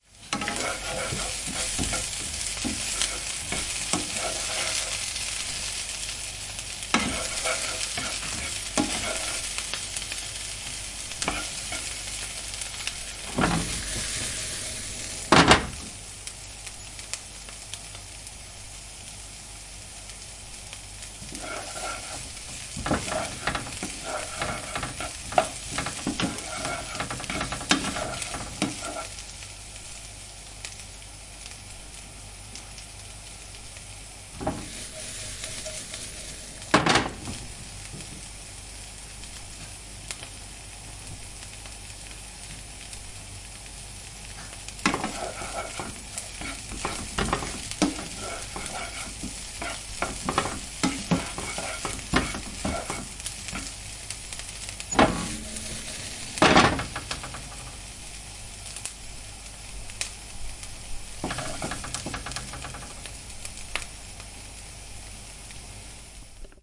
Food and Drink » Burger Fry
描述：Tasty Burger sizzling away in a pan, I occasionally move it slightly.
标签： fry frying cook burger sizzling food sizzle
声道立体声